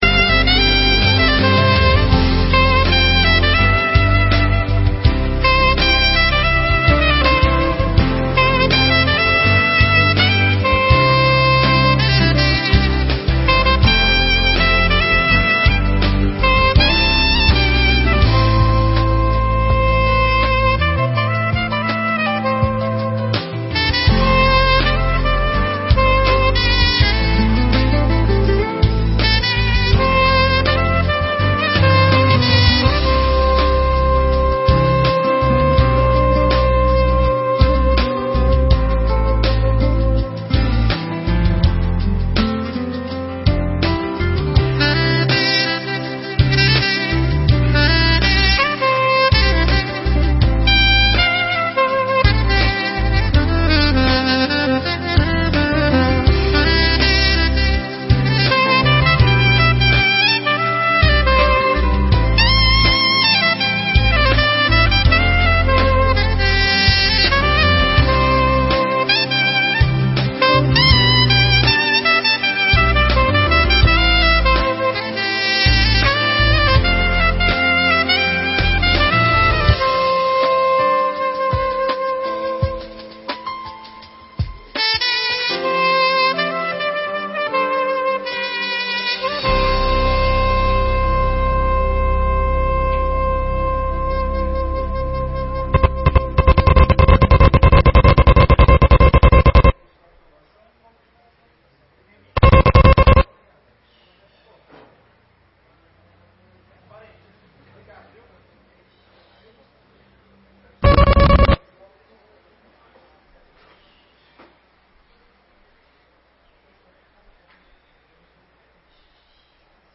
1ª Sessão Ordinária de 2017